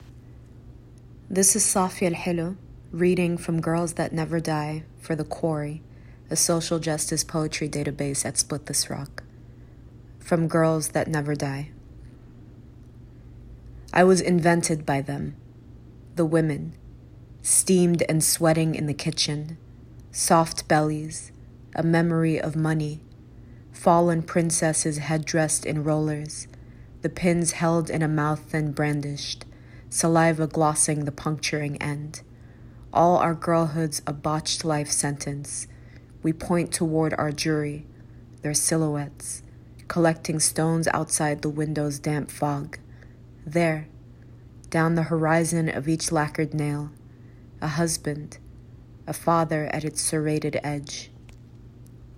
Listen as Safia Elhillo reads "from GIRLS THAT NEVER DIE."